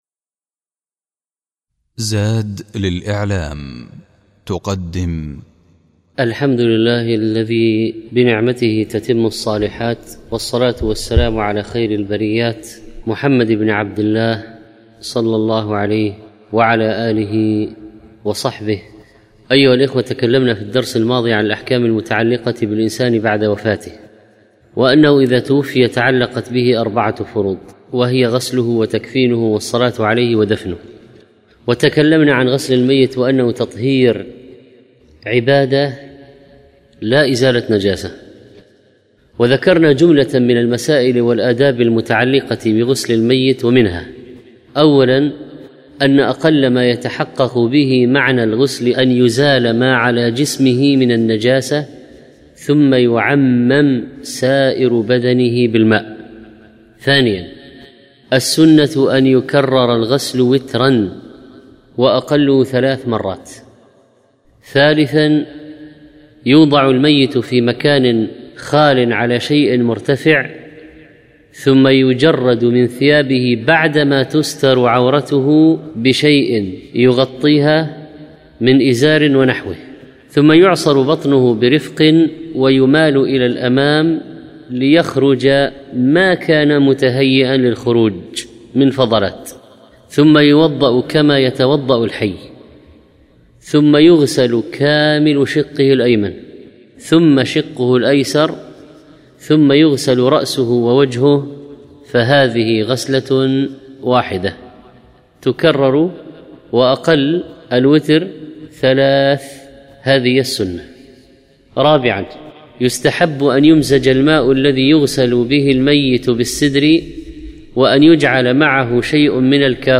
27 ربيع الأوّل 1431 الزيارات: 3224 تحميل تحميل ملف صوتي تحميل ملف فيديو أحكام الجنائز - الدرس الخامس تكلم الشيخ في هذا الدرس عن العمل إذا تعذر تغسيل الميت، وحكم تغسيل المسلم للكافر والعكس، وحكم غسل الشهيد، والسقط، وصفة الغاسل، ومن يحضر الغسل، وحكم من دفن دون غسل، وتكفين الميت وصفة الكفن ....